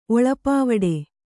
♪ oḷapāvaḍe